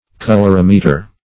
Colorimeter \Col`or*im"e*ter\, n. [Color + -meter: cf. F.